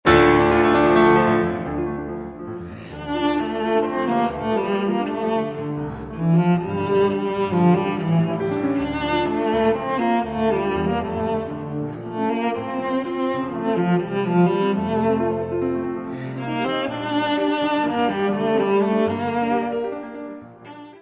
violoncelo
piano
: stereo; 12 cm + folheto
Music Category/Genre:  Classical Music